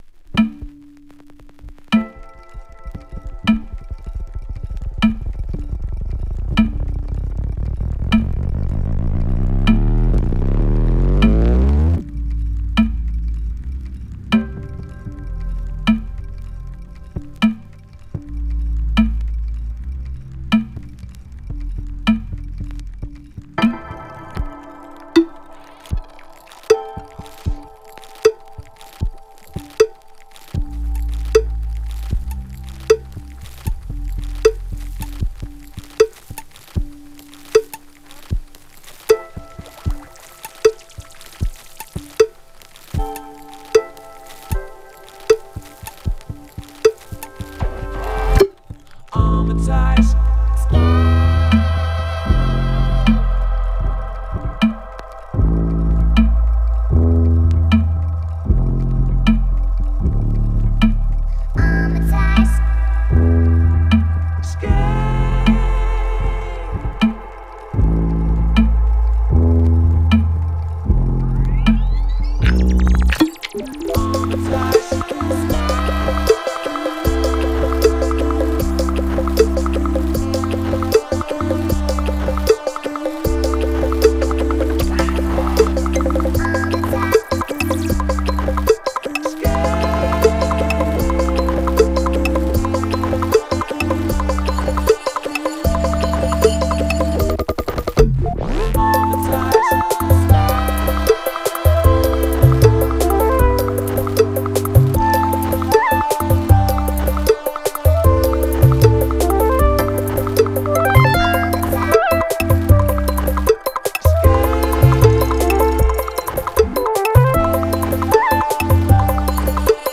> BASS / DUB STEP / DRUM N' BASS